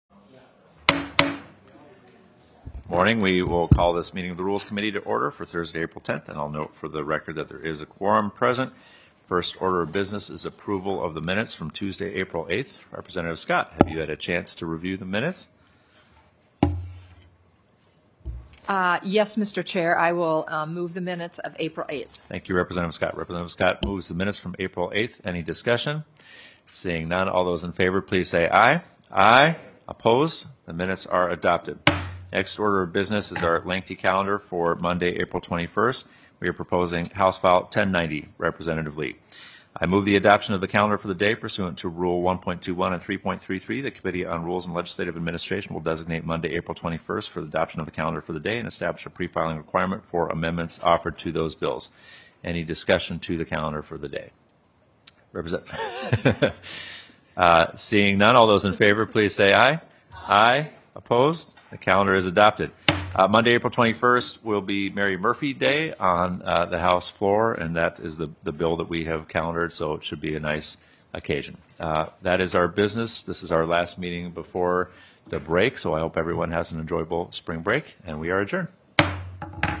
Rules and Legislative Administration Committee Minutes
Representative Jamie Long, Co-Chair of the Committee on Rules and Legislative Administration, called the meeting to order at 10:00 am on Thursday, April 10, 2025 in Capitol G23.